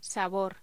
Locución: Sabor
Sonidos: Voz humana